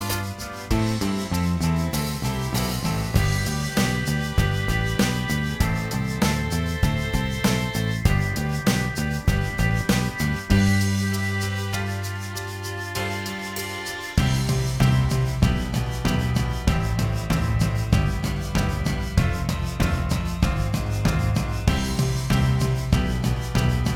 Minus Lead Guitar Pop (1960s) 3:35 Buy £1.50